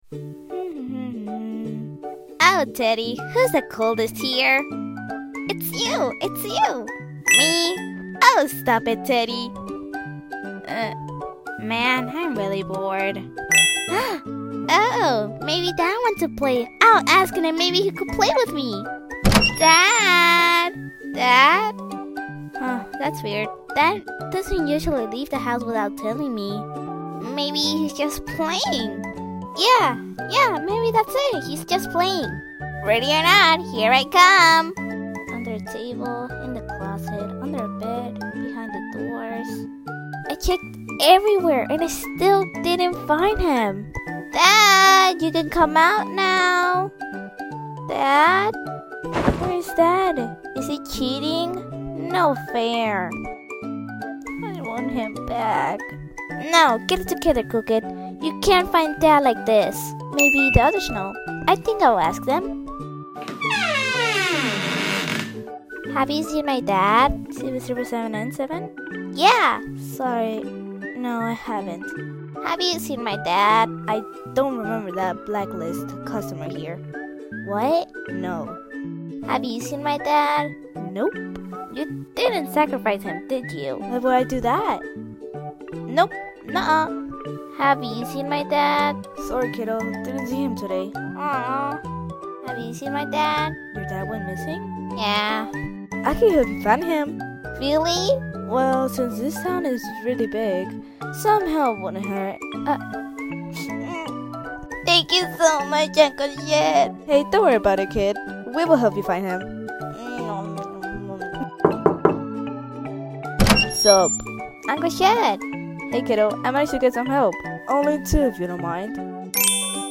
Voice Acting A Comic! Credits Sound Effects Free Download